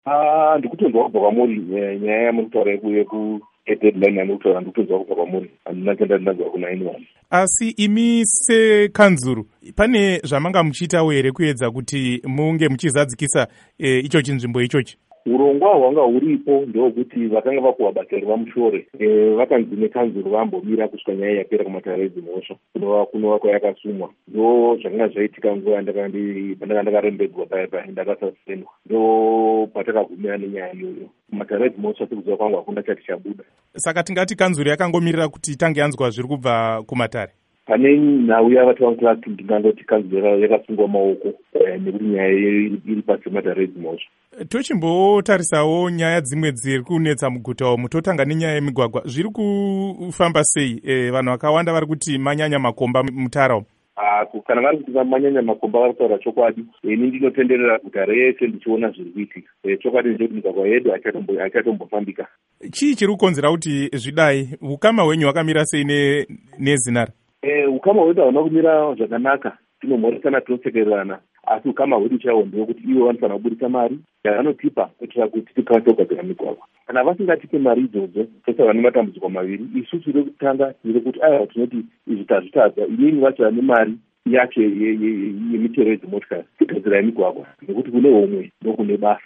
Hurkuro naVaBernard Manyenyeni